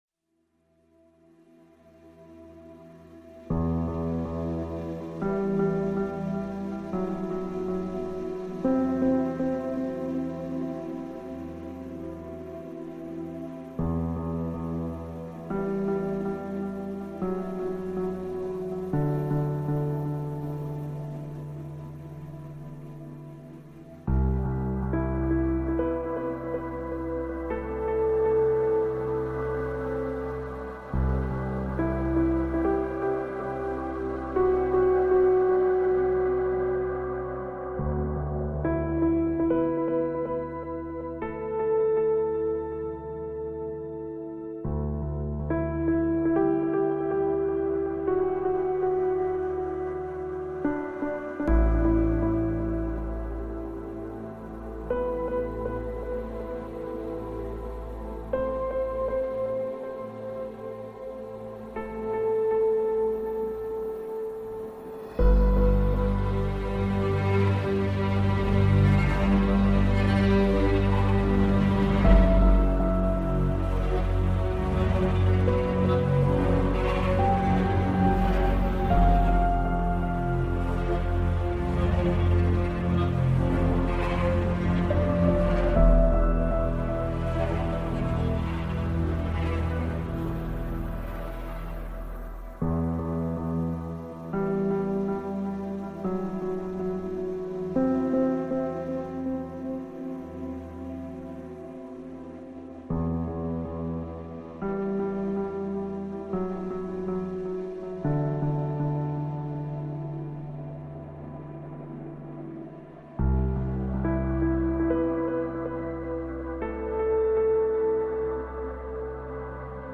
lonely.mp3